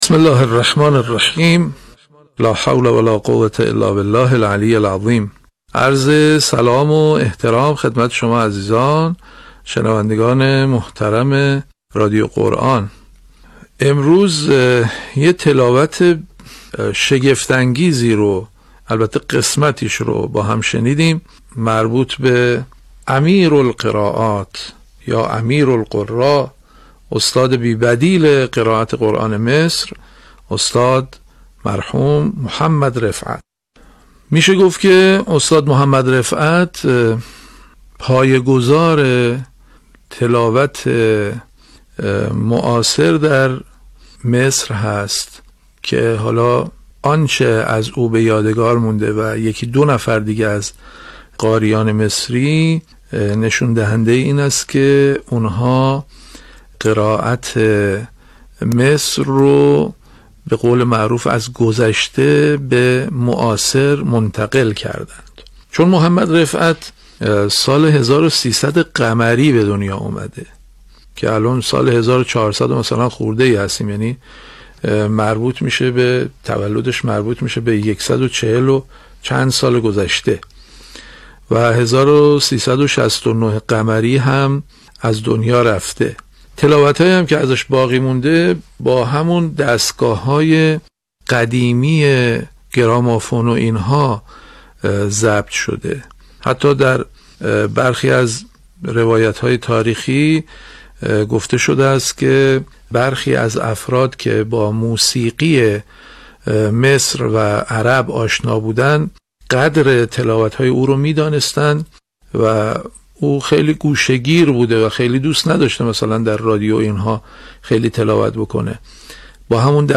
یادآور می‌شود، این تحلیل در برنامه «اکسیر» به تاریخ 1 شهریورماه ۱۳۹۷ ساعت ۱۶:۳۰ تا ۱۸ از شبکه رادیویی قرآن پخش شد.
پس از هر تلاوت نیز دو کارشناس در زمینه مباحثی همچون آموزش، تحلیل و بررسی تکنیک‌ها و فنون تلاوت و ... مواردی را مطرح می‌کنند.